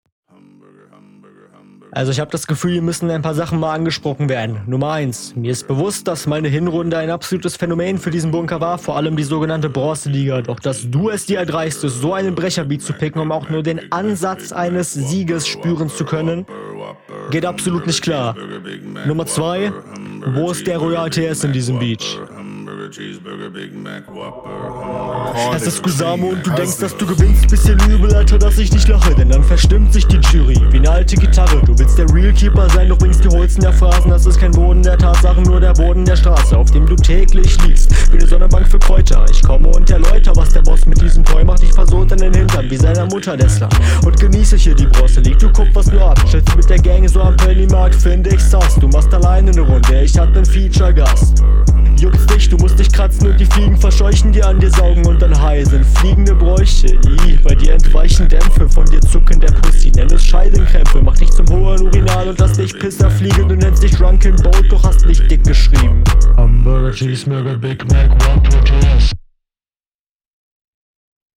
Das Intro klingt vielversprechend.